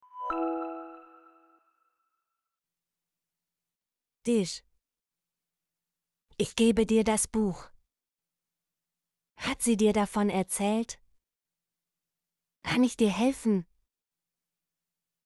dir - Example Sentences & Pronunciation, German Frequency List